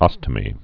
(ŏstə-mē)